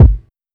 Kick (16).wav